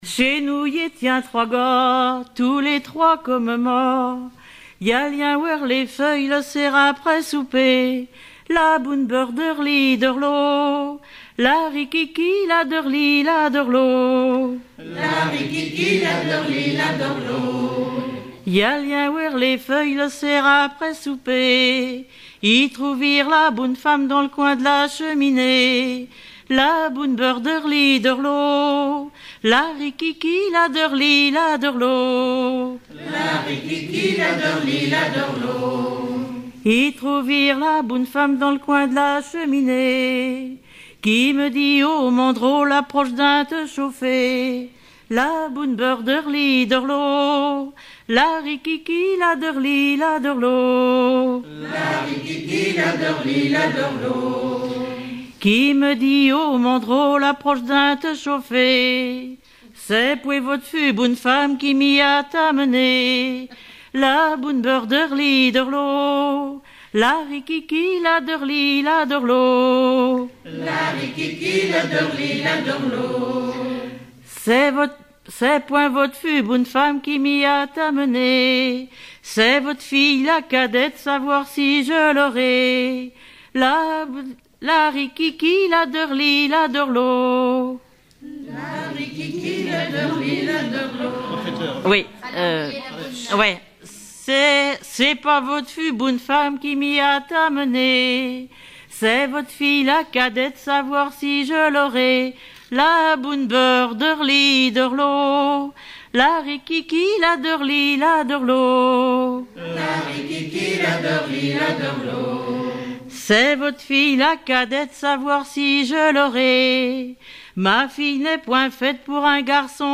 Langue Patois local
Genre laisse
Chansons traditionnelles et populaires
Pièce musicale inédite